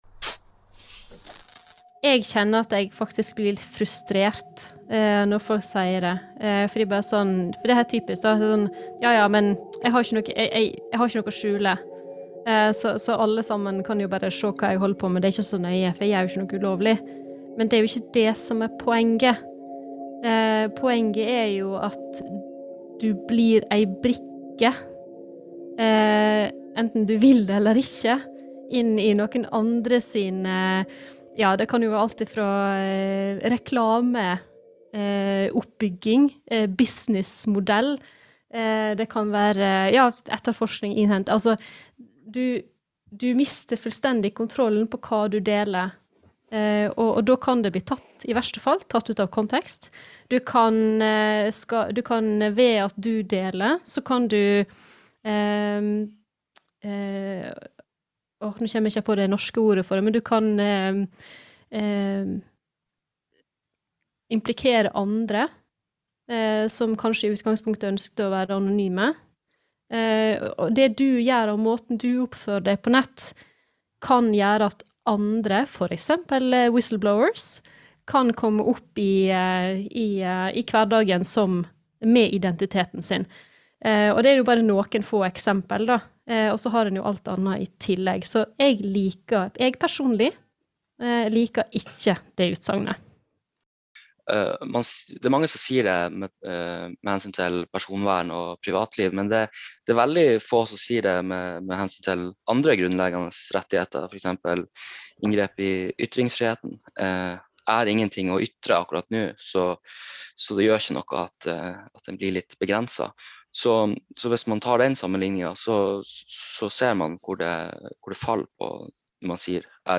Vær obs: i serien snakkes det kanskje tidvis med utestemme.